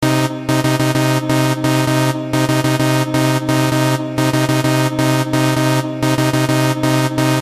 锯齿合成器2
描述：用锯式振荡器制作，一个音的节奏感很好
Tag: 130 bpm Dance Loops Synth Loops 1.24 MB wav Key : Unknown